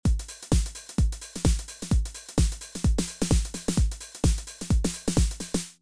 Now, our drum loop is three bars long—it’s variation A (one bar), followed by variation B (one bar) and variation C (one bar).